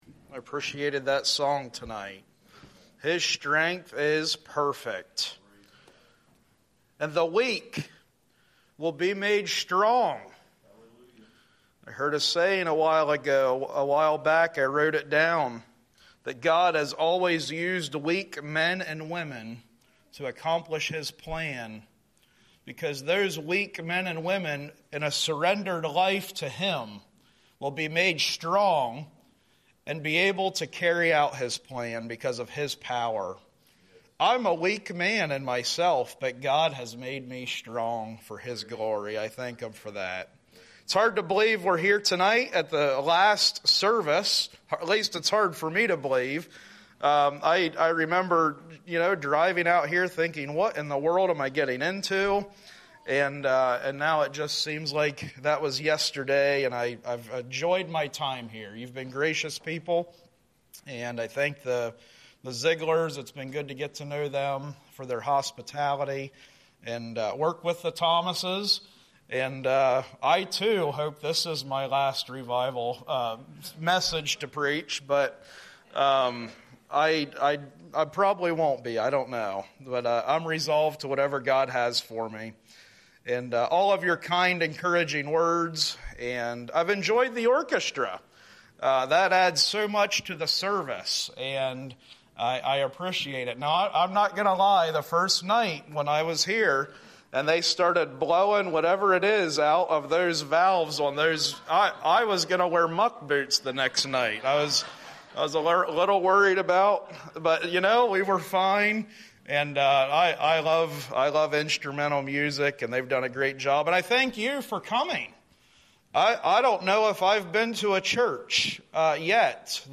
Save Audio A sermon